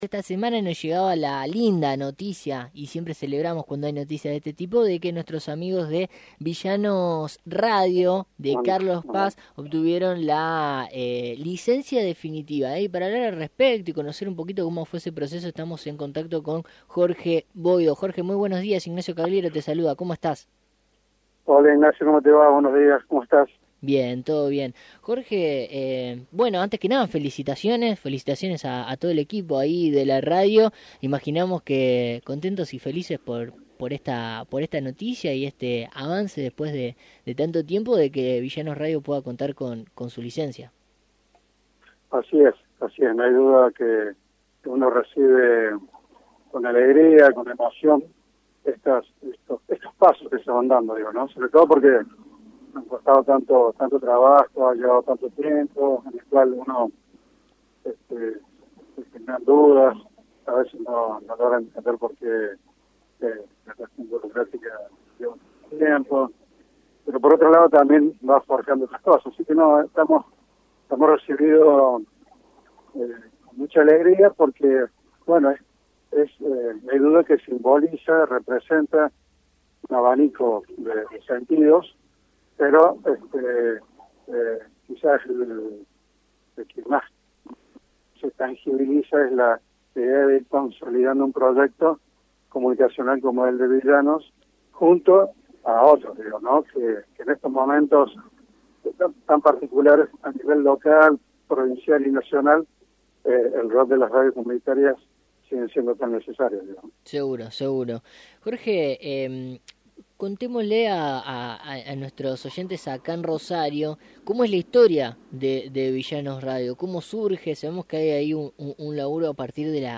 entrevistó